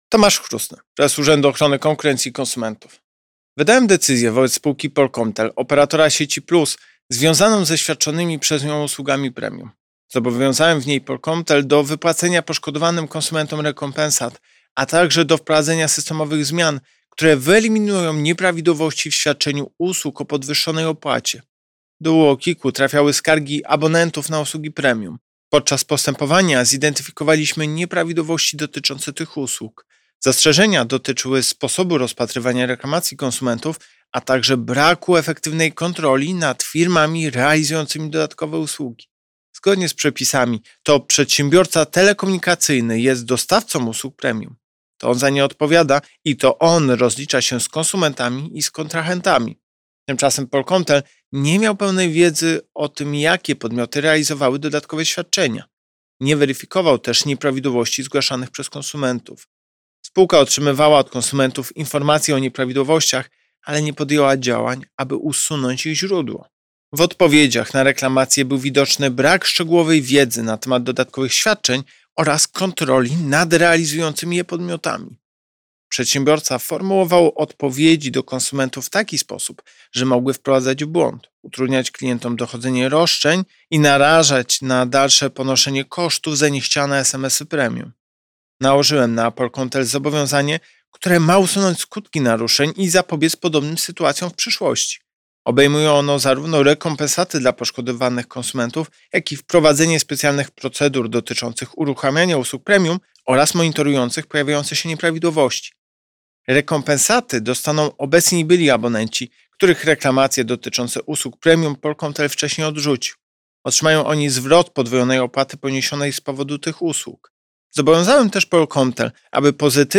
Wypowiedź Prezesa UOKiK Tomasza Chróstnego z 2 czerwca 2021 r..mp3